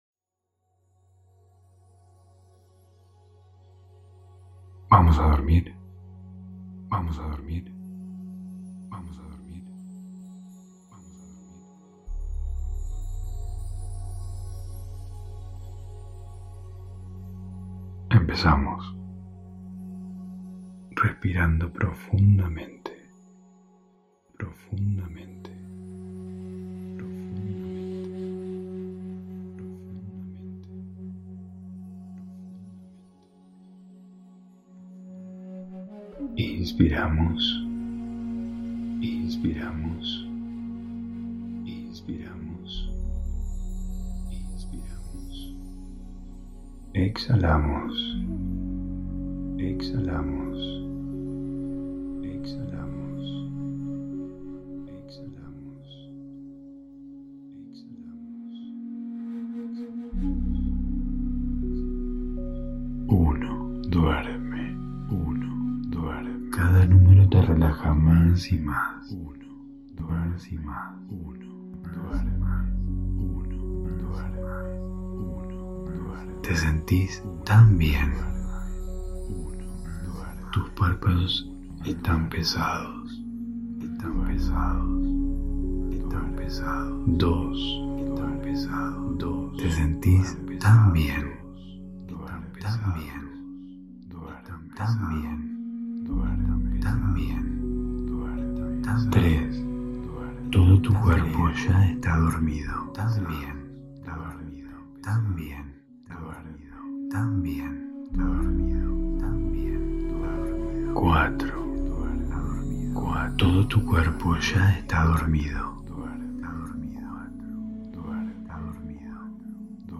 Hipnosis Progresiva para dormir ✨
[Altamente recomendable escucharlo con auriculares ] Hosted on Acast.